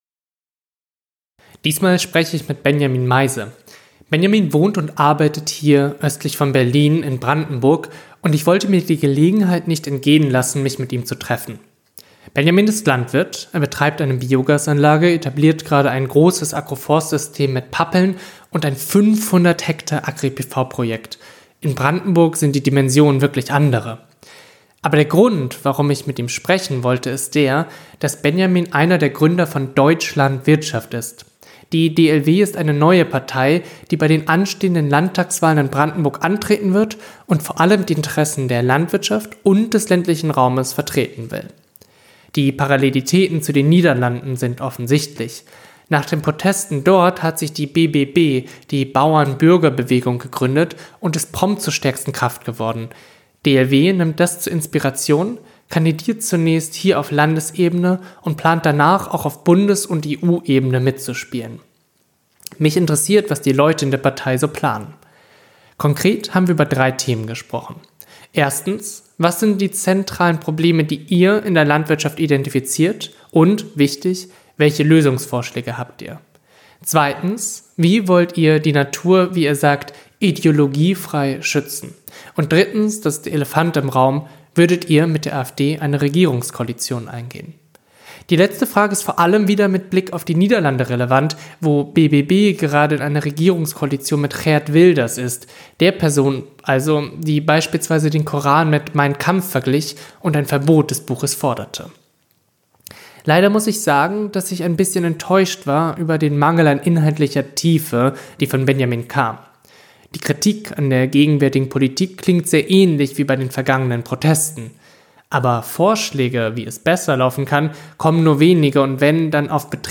[Interview]